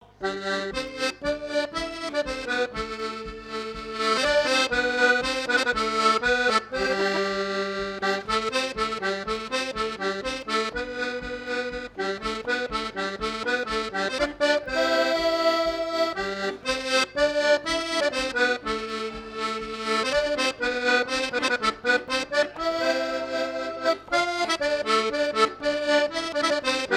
Mémoires et Patrimoines vivants - RaddO est une base de données d'archives iconographiques et sonores.
danse : marche
Fête de l'accordéon
Pièce musicale inédite